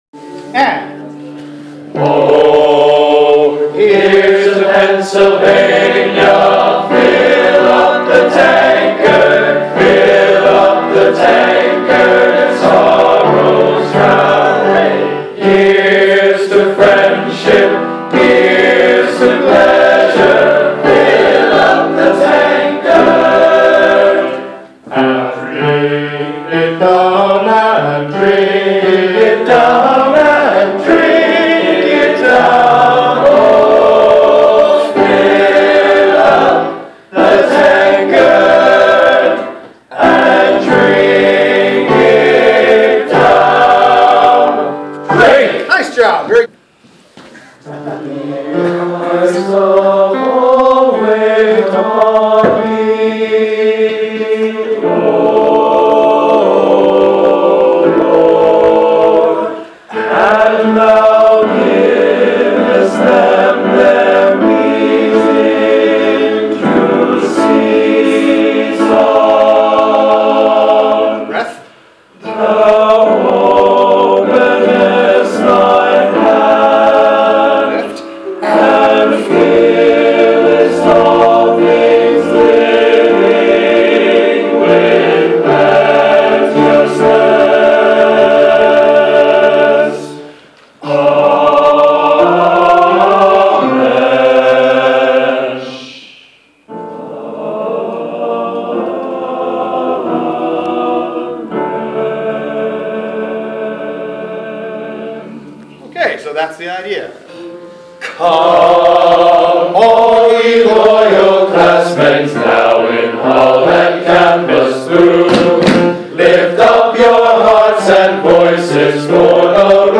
Glee Club Sightsing